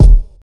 Dilla Kick.wav